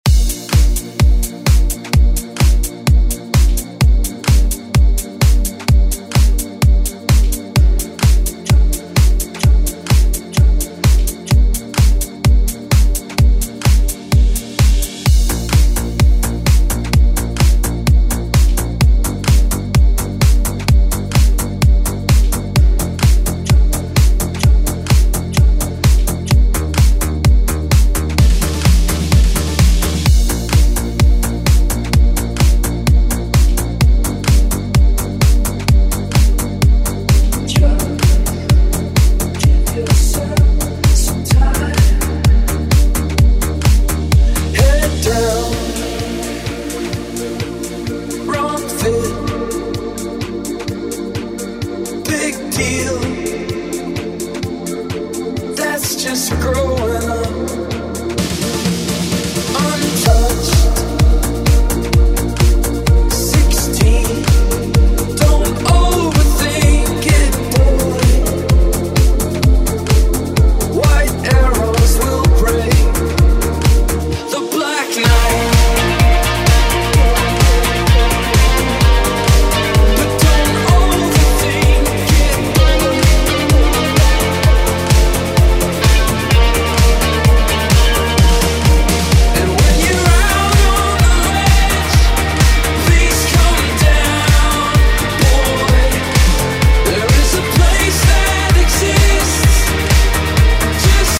Genres: DANCE , RE-DRUM , TOP40
Clean BPM: 130 Time